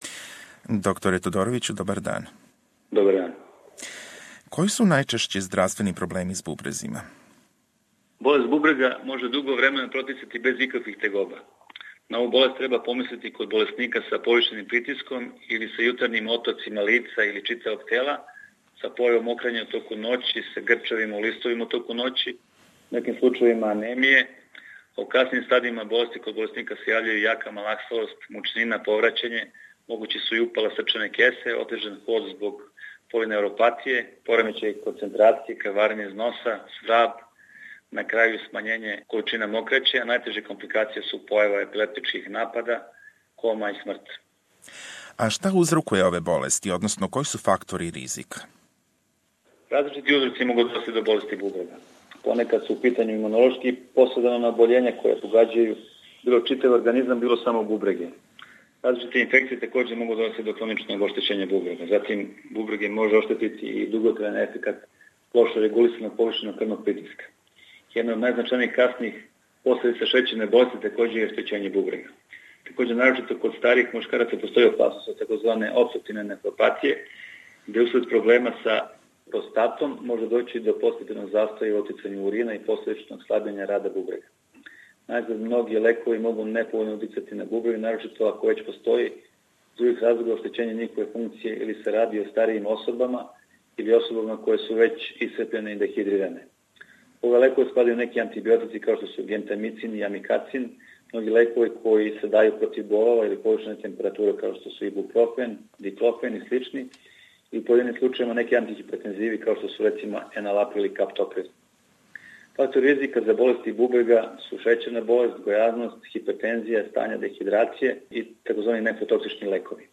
У разговору